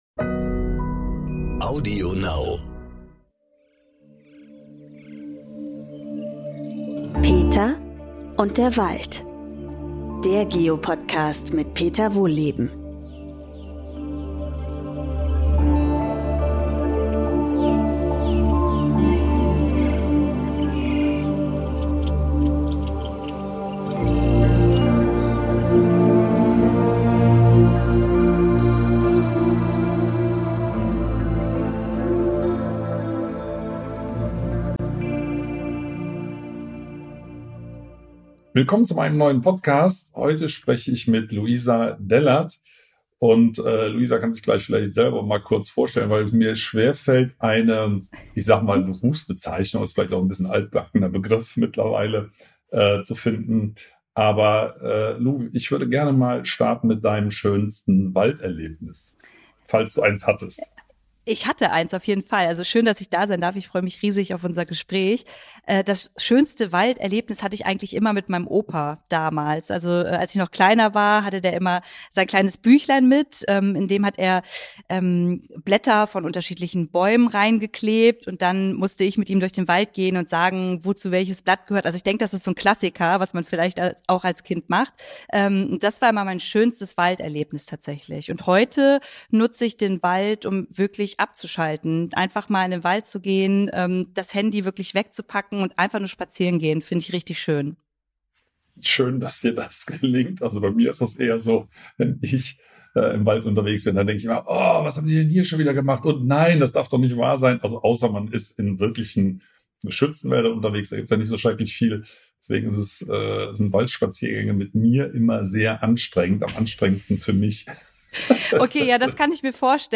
Über diese Fragen unterhalten sich Peter Wohlleben und die Autorin, Moderatorin und Influencerin Louisa Dellert.